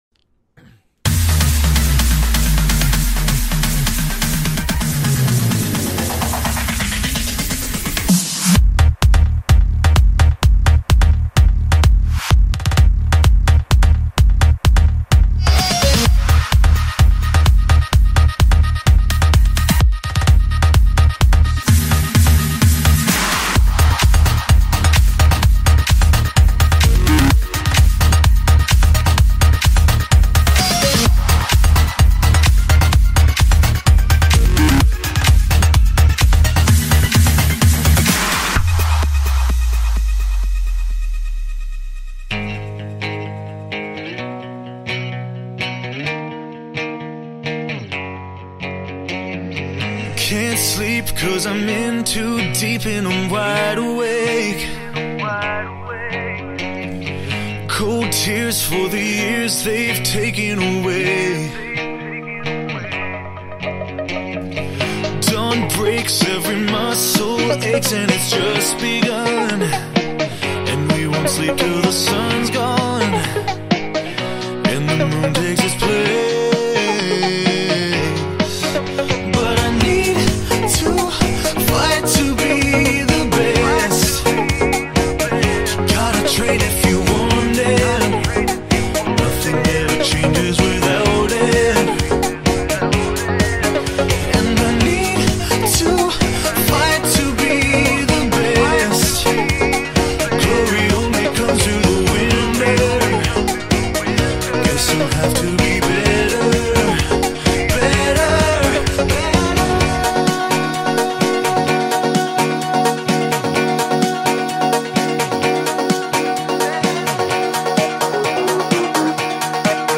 Sung by me.